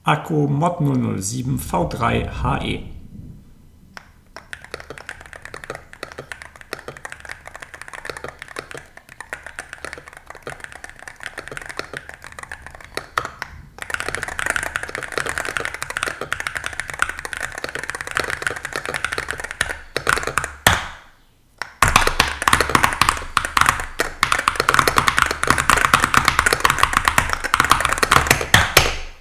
Es geht der Tastatur nicht um leise, es geht um „Ploppen“: Anschläge „knallen“ ein wenig, klingen knackiger als gewöhnlich, vergleichbar vielleicht mit einem Sportauspuff am Auto.
Dazu kommt die ungedämmte Leertaste, die akustisch ein wenig zu sehr heraussticht – im Premium-Segment ein Faux Pas.
Der knackige, klare Rückhall der Standardkonfiguration erschien im Test als der gelungenere.